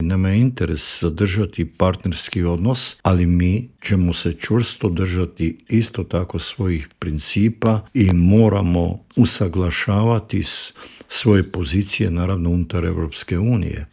O tome se proteklih dana razgovaralo i u Vladi, a posebni savjetnik premijera Andreja Plenkovića i bivši ministar vanjskih i europskih poslova Mate Granić u Intervjuu Media servisa rekao je da je Ured predsjednika informiran o svemu što radi Vlada RH.